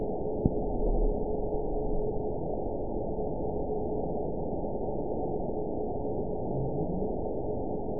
event 922215 date 12/28/24 time 08:57:46 GMT (4 months ago) score 9.57 location TSS-AB04 detected by nrw target species NRW annotations +NRW Spectrogram: Frequency (kHz) vs. Time (s) audio not available .wav